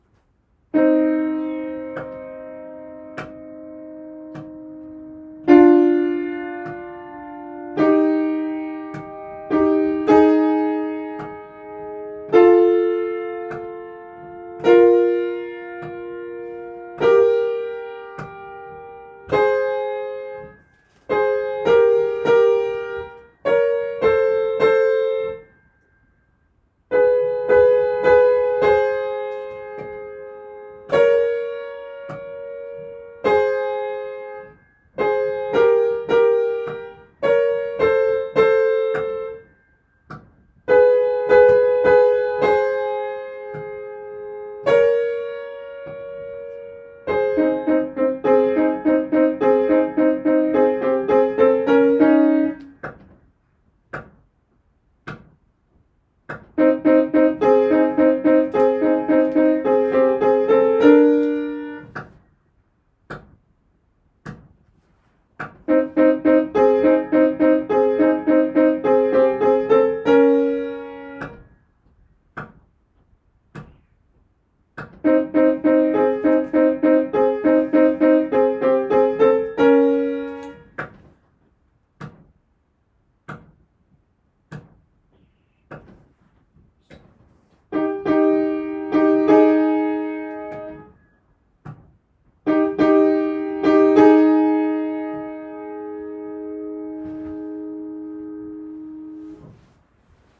音とり音源
ソプラノ